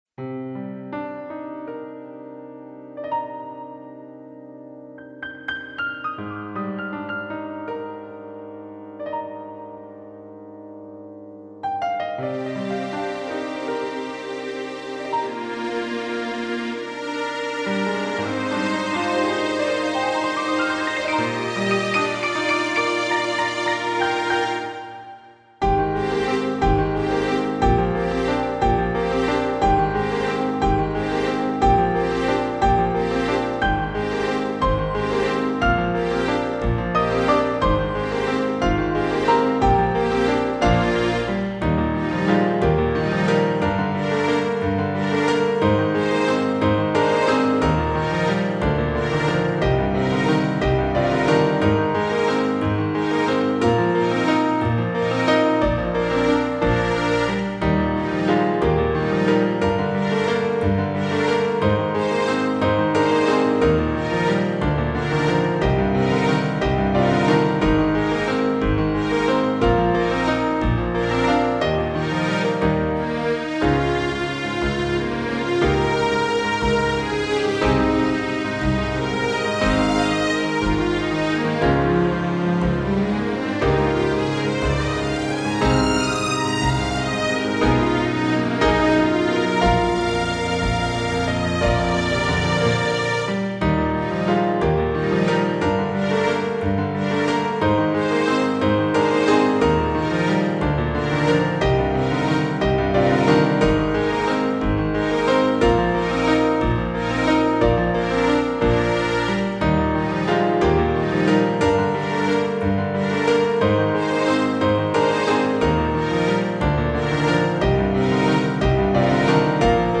前半は暗く、後半は明るい曲。